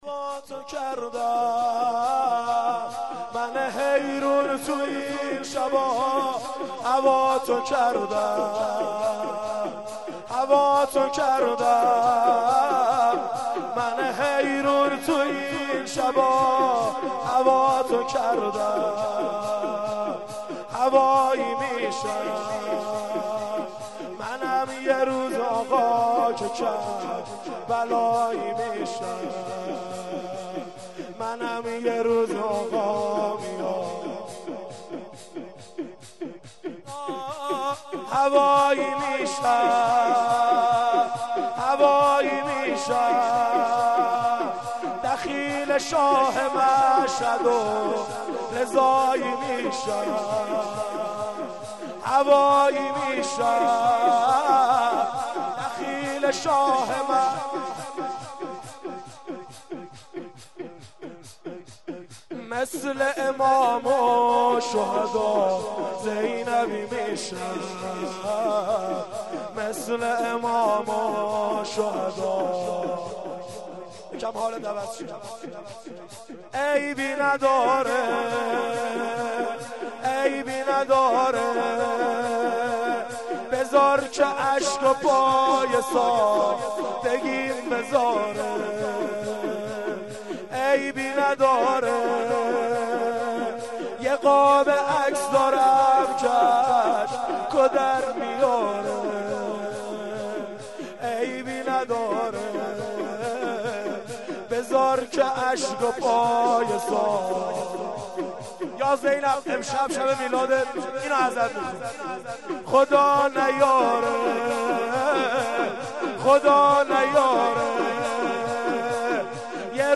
هیئت لواء الزینب شیراز